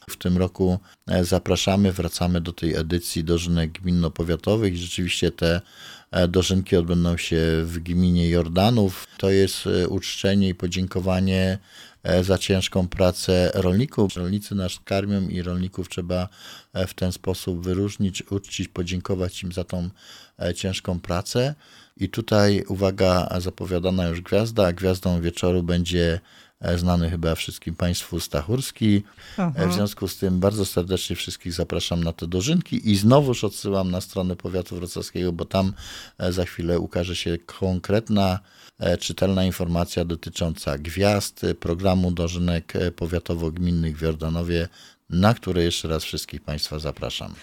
Kolejną atrakcją dla mieszkańców powiatu będą dożynki gminno- powiatowe w Jordanowie Śląskim, 26 sierpnia. To podziękowanie dla rolników, za ich ciężką pracę – dodaje starosta.